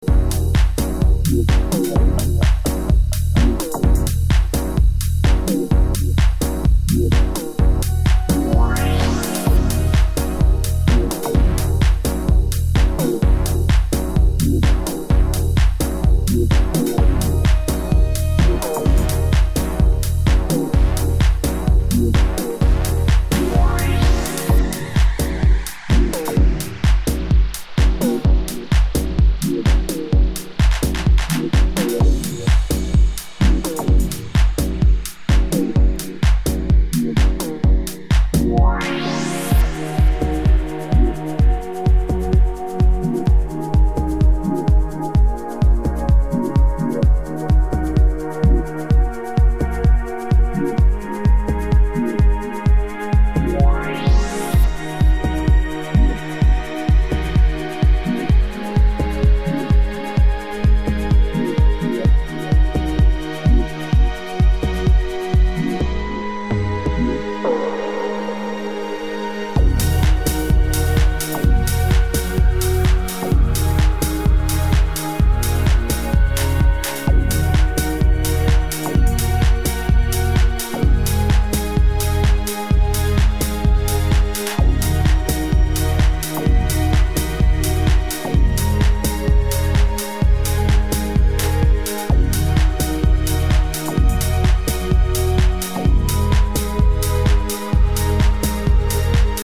Detroit influenced club/techno tracks
powerful as always.